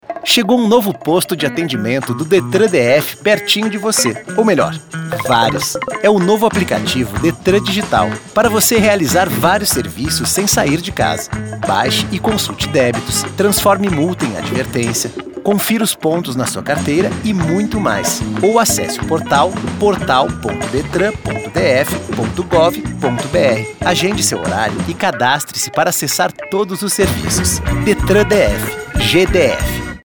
DetranDigital_Spot_Mix02.mp3